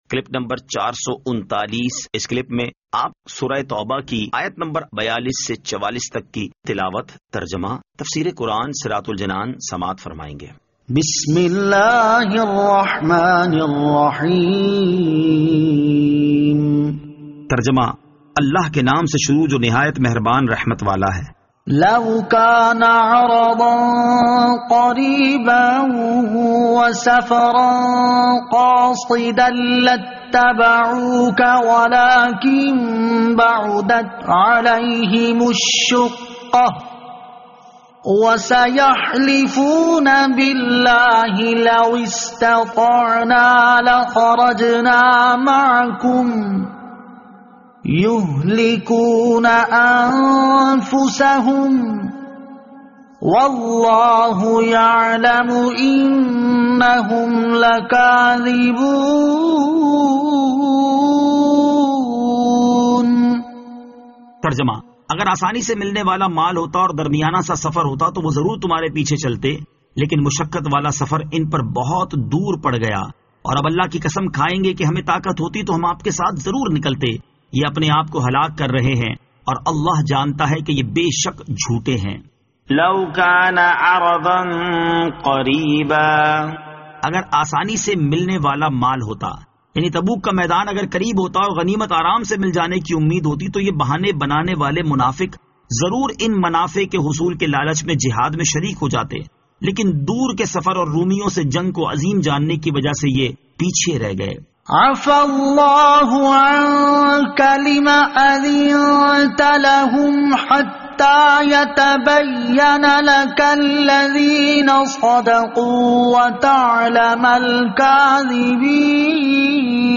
Surah At-Tawbah Ayat 42 To 44 Tilawat , Tarjama , Tafseer